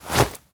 foley_object_push_pull_move_05.wav